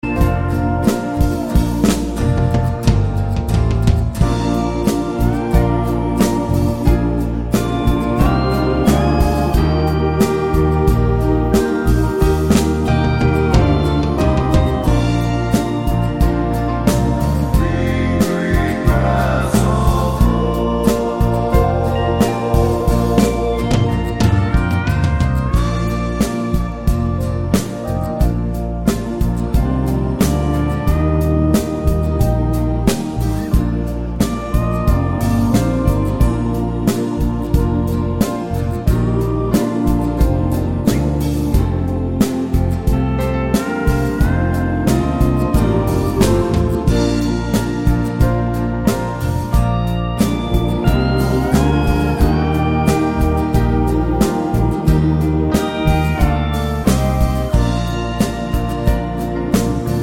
no Backing Vocals Crooners 3:19 Buy £1.50